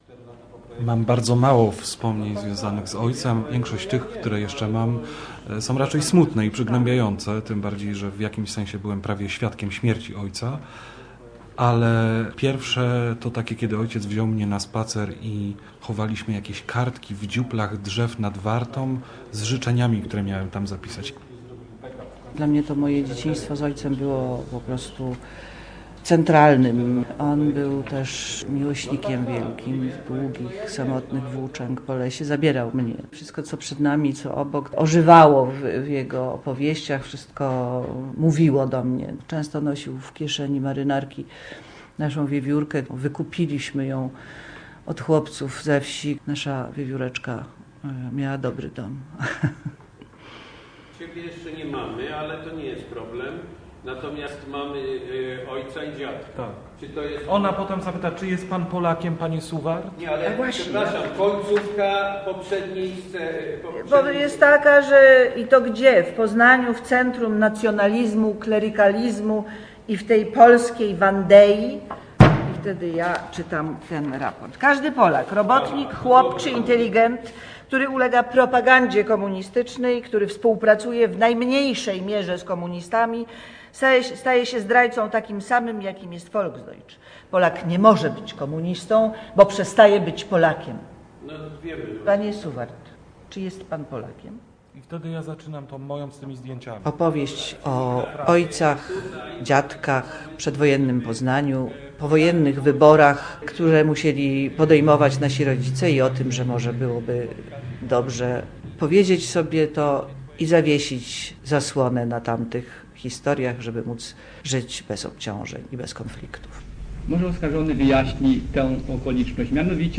Dwie niepodobne historie - reportaż